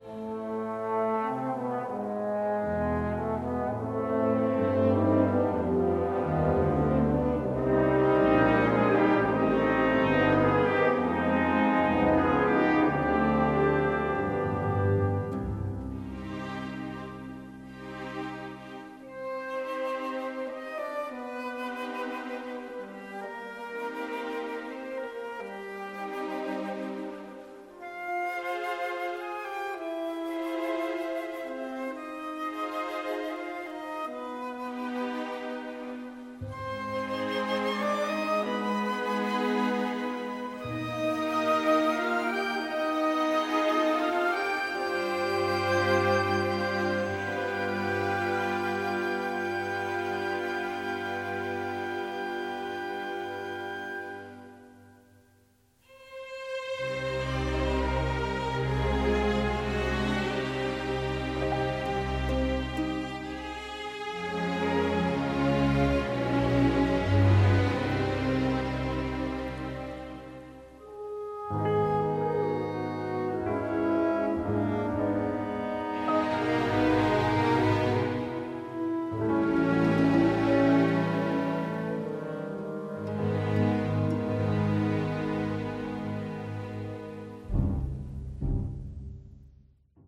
the score is operatic in its power